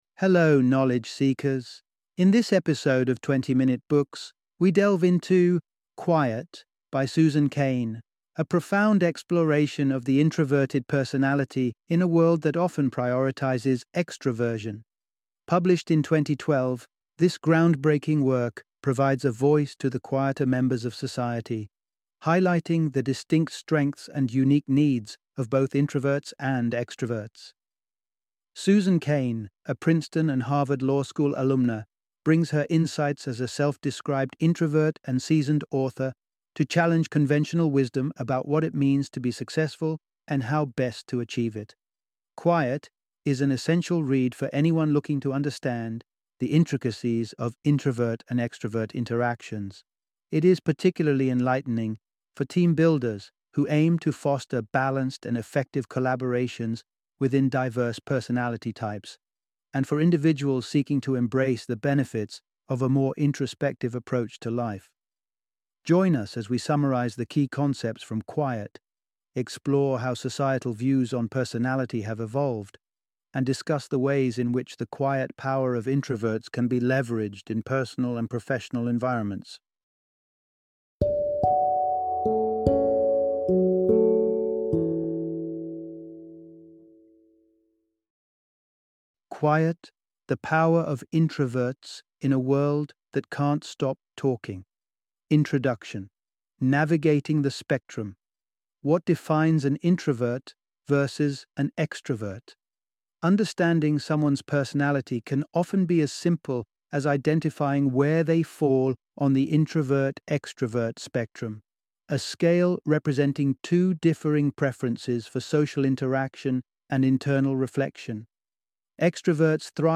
Quiet - Audiobook Summary